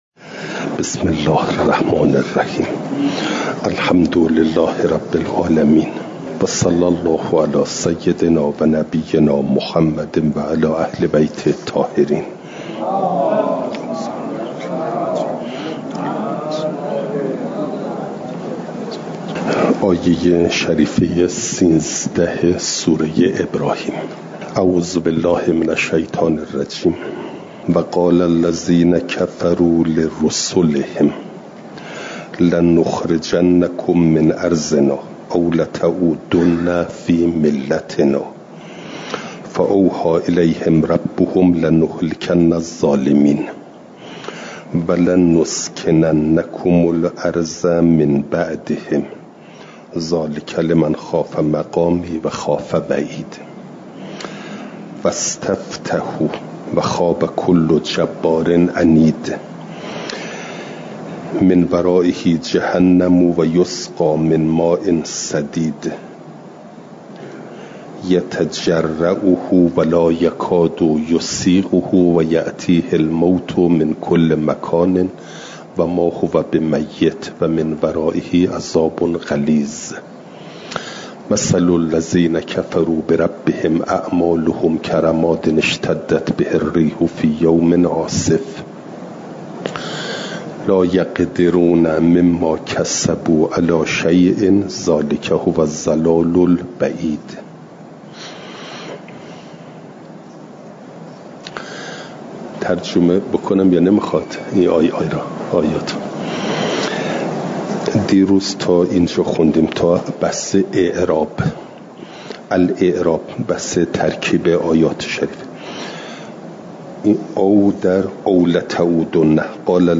مجمع البیان | جلسه ۸۲۱ – دروس استاد